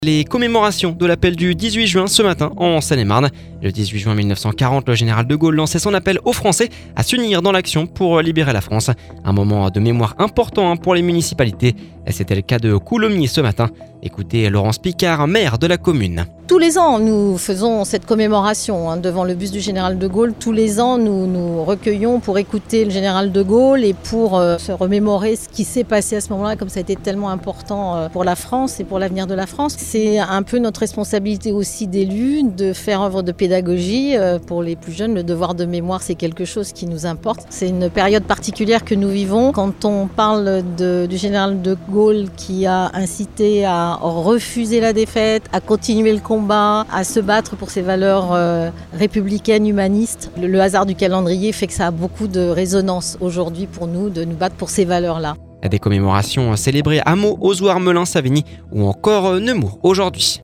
C’était le cas de Coulommiers ce matin, écoutez Laurence Picard, maire de la commune…